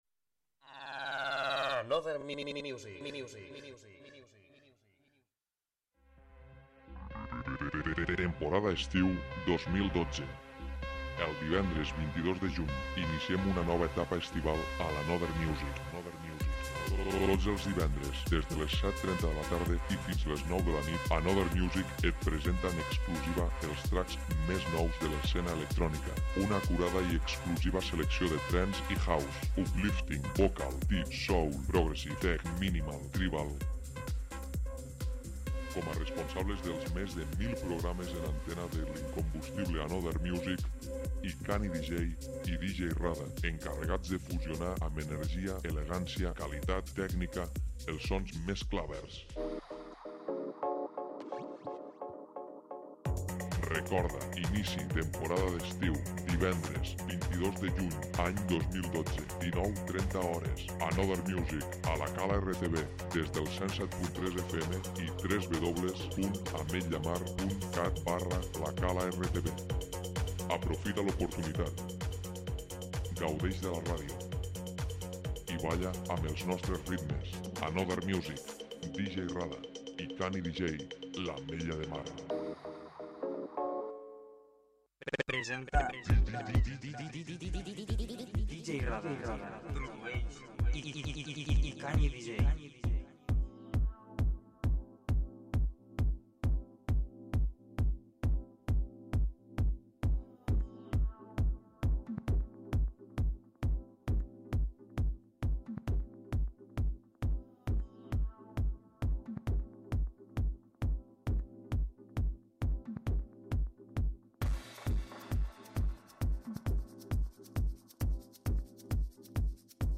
produït en directe el divendres 27 de juliol de 2012
novetats House
fusió de ritmes exclusius, amb calitat, energía i tècnica
Deep/Soulful/Vocal/Tech/Minimal.